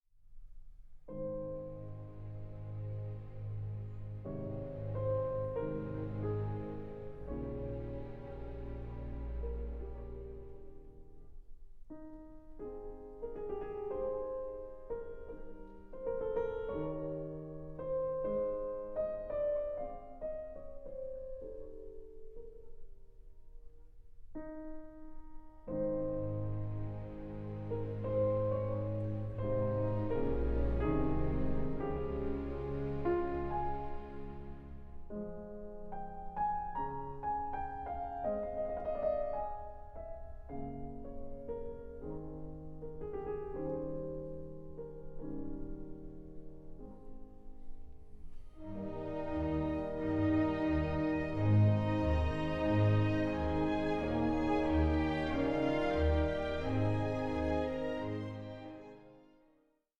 (48/24, 88/24, 96/24) Stereo  26,95 Select